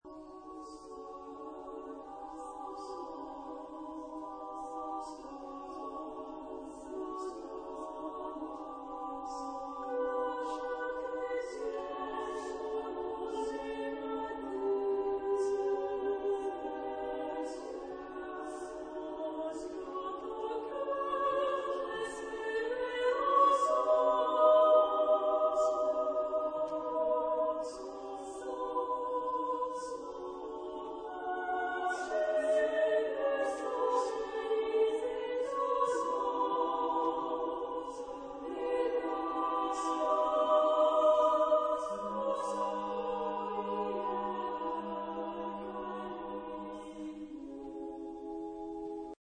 Género/Estilo/Forma: Profano ; Melodia
Carácter de la pieza : poliritmico
Tipo de formación coral: SSSAAA  (6 voces Coro femenino )
Tonalidad : do sostenido menor